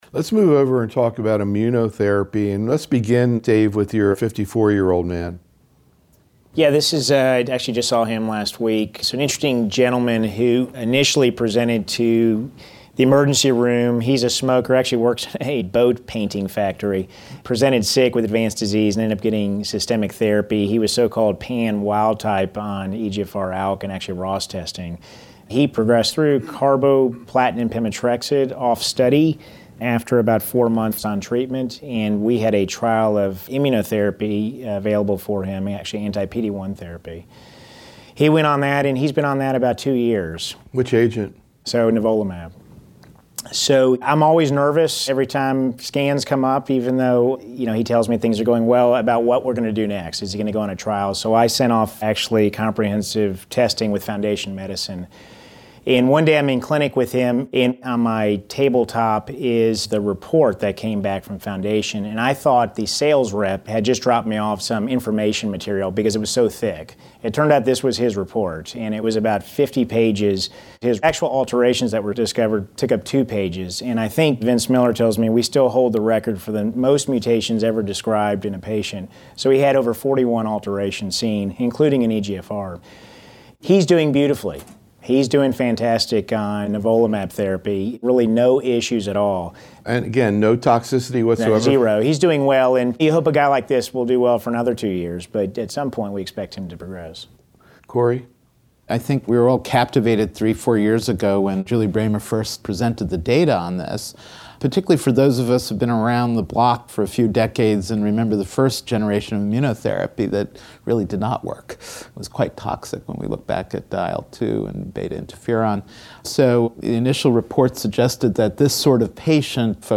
In these audio proceedings from a closed Think Tank meeting held in January 2015, the invited faculty discuss and debate some of the key clinical management issues in lung cancer as well as promising research strategies in this area. The roundtable discussion focused on key presentations and papers and actual cases managed in the practices of the faculty in which these data sets factored into their decision-making.